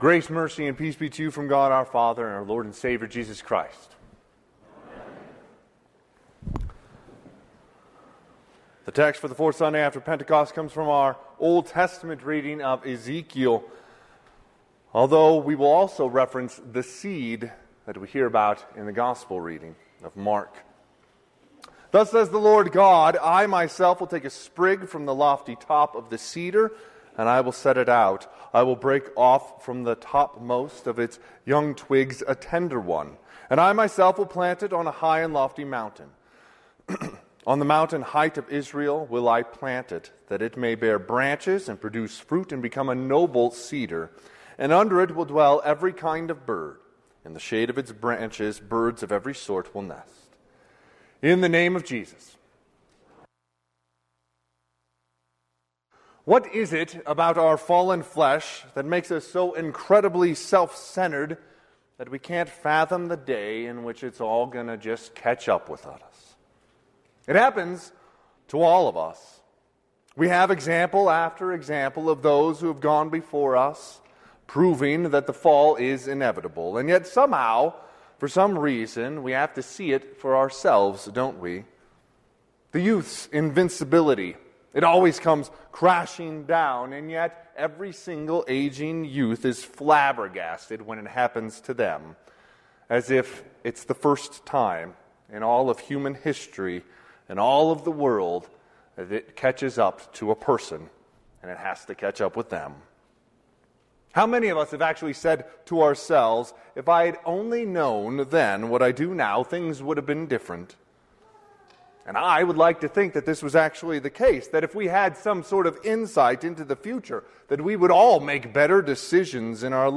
Sermon - 6/16/2024 - Wheat Ridge Lutheran Church, Wheat Ridge, Colorado